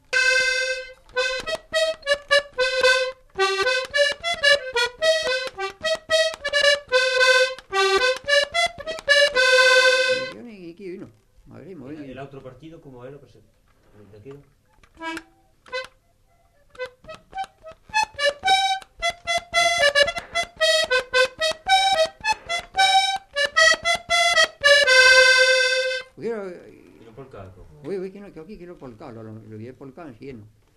Genre : morceau instrumental
Instrument de musique : accordéon diatonique
Danse : polka